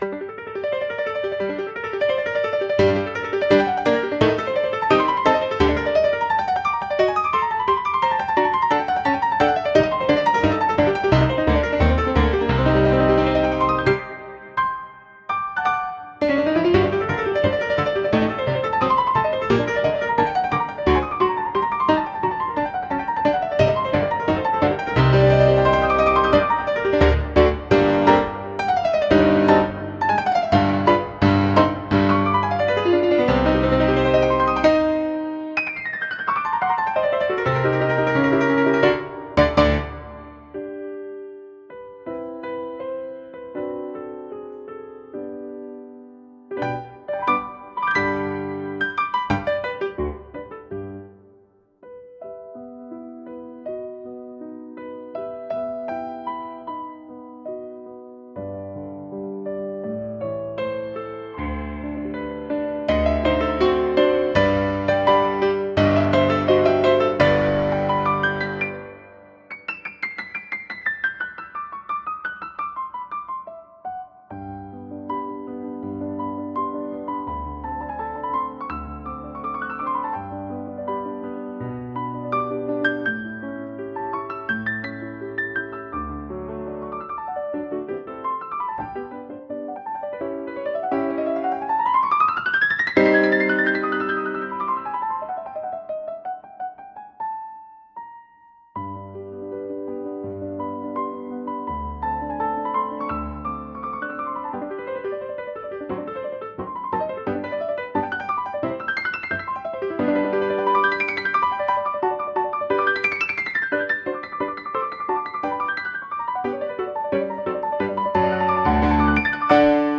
超絶技巧のピアノ曲
クラシック史のロマン派のピアノソロ曲のような、早いパッセージと複雑な和音からなるピアノ曲を生成してみます。
Piano
Instrumental only, No vocals, Virtuosic romantic-style solo piano composition with rapid arpeggios, wide dynamic range, and dramatic tempo changes, Complex harmonic progressions and expressive rubato, Energetic, emotional, and technically demanding performance
楽曲としてはロマン派っぽさもあっていい曲だと思うのですが、音質の悪さがもったいない惜しい結果となりました。